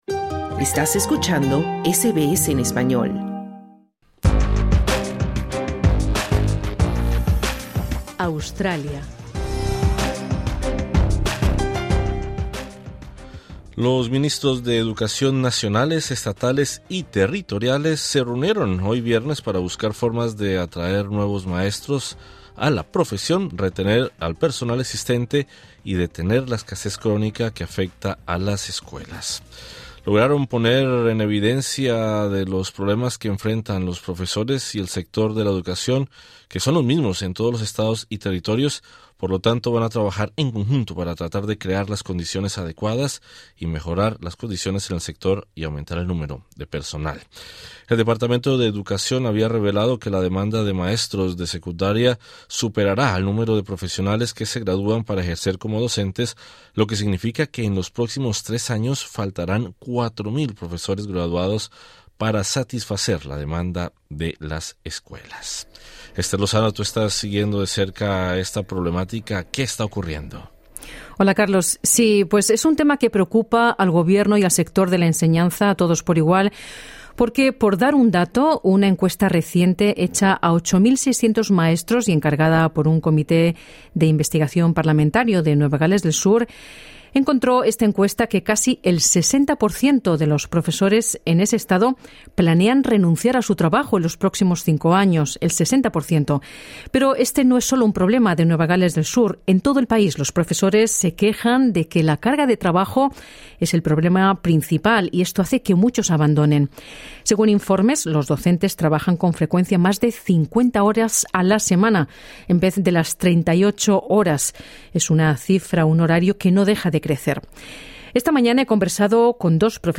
Escucha las voces de profesores afectados y el análisis de los expertos.